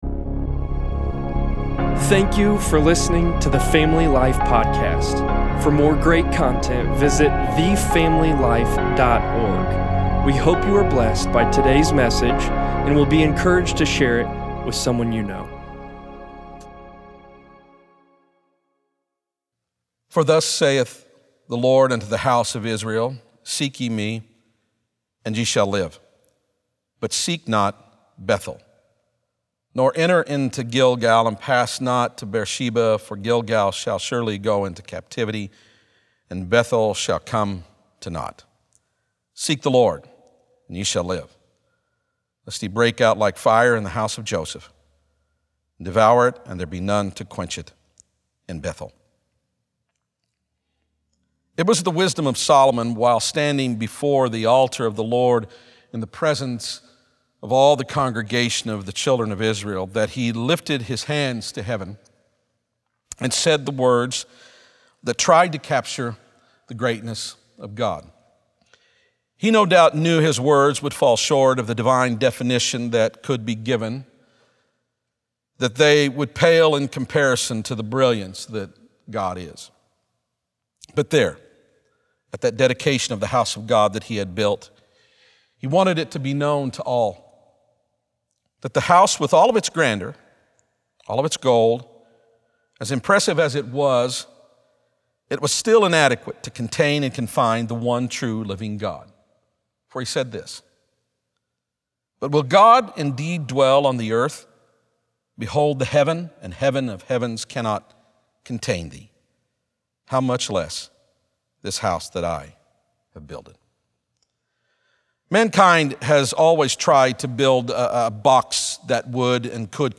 6.14.20sermon_p.mp3